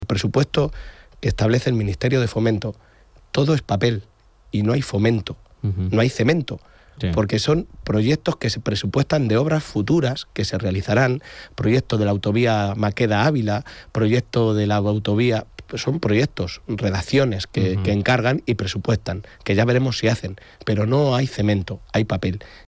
En una entrevista en Onda Cero Castilla-La Mancha
Cortes de audio de la rueda de prensa